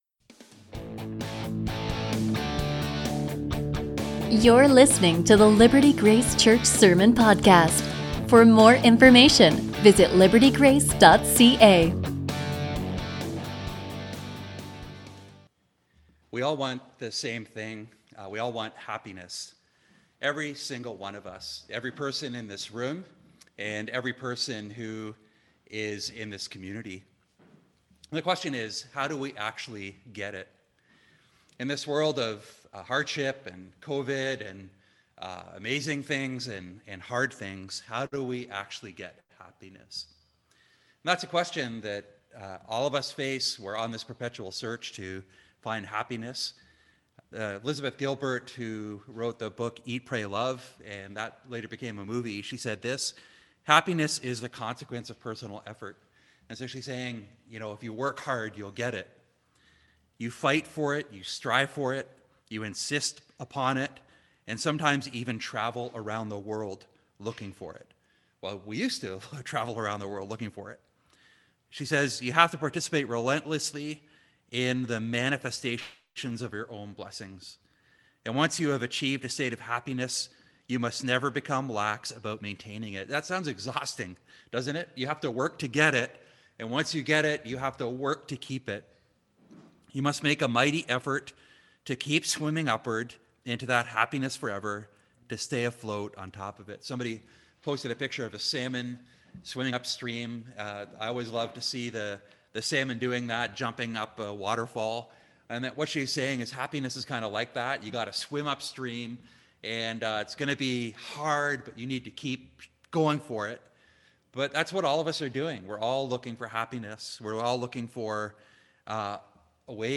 A sermon from Ecclesiastes 1:12-2:26